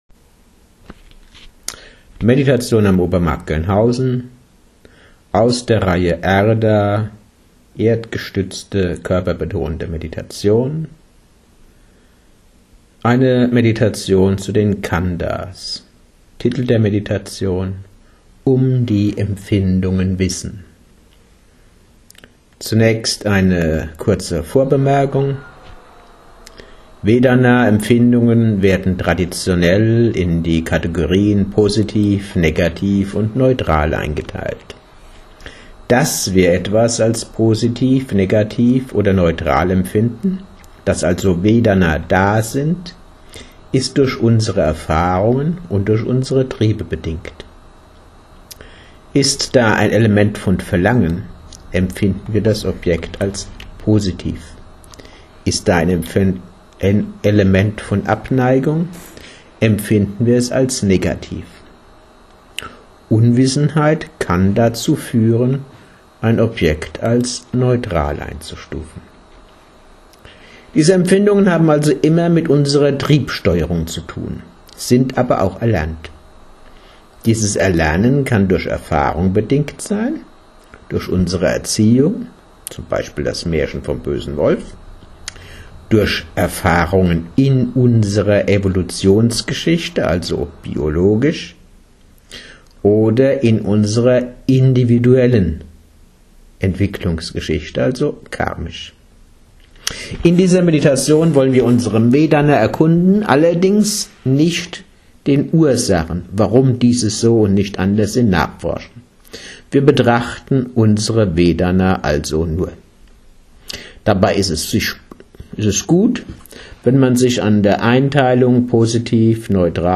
Khandha-Meditation: Um die Empfindungen wissen (Audio-Datei, 31 Min.)